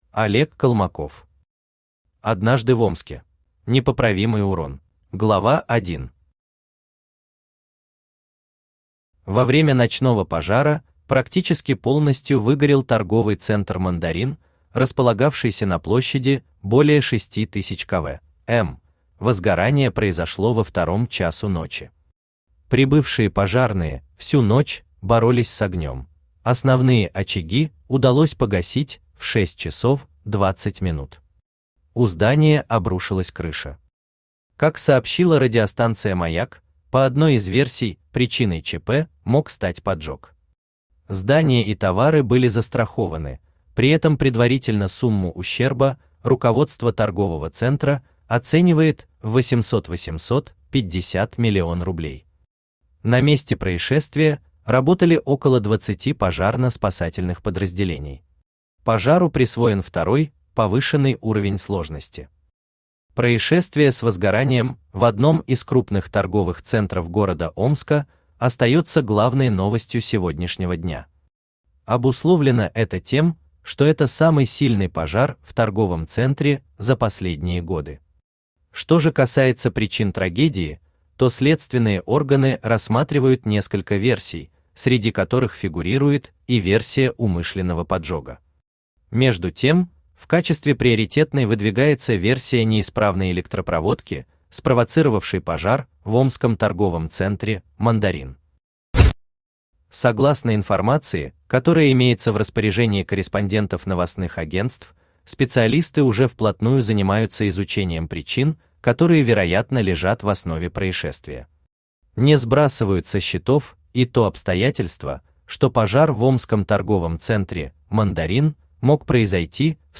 Аудиокнига Однажды в Омске | Библиотека аудиокниг
Aудиокнига Однажды в Омске Автор Олег Колмаков Читает аудиокнигу Авточтец ЛитРес.